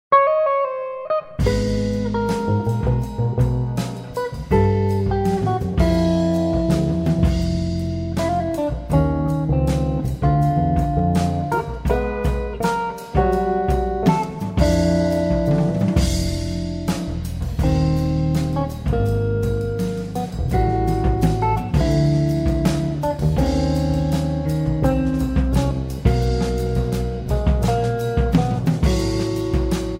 Guitars
Bass
Drums